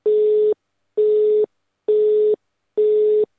busy.wav